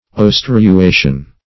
Search Result for " oestruation" : The Collaborative International Dictionary of English v.0.48: oestruation \oes`tru*a"tion\, n. (Physiol.)
oestruation.mp3